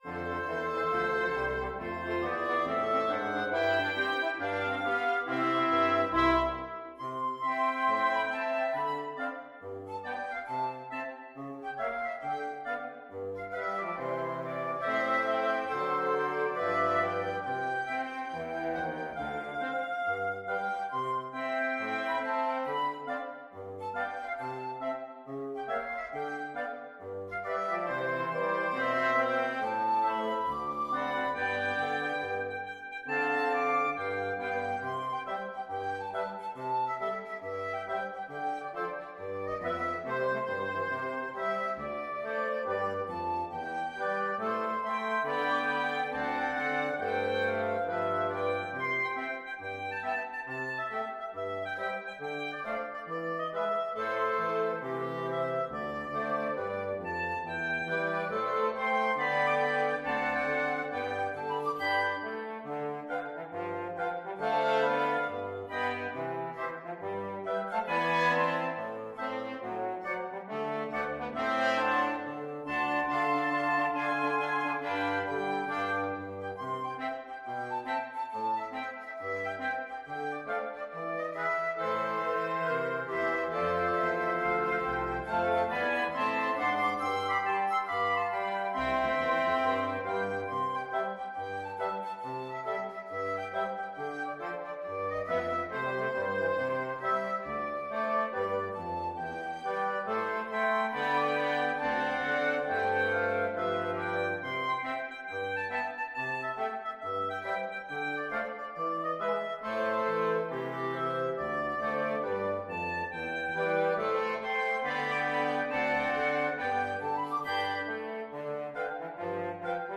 FluteOboeClarinetFrench HornBassoon
With a swing =c.69
4/4 (View more 4/4 Music)
Pop (View more Pop Wind Quintet Music)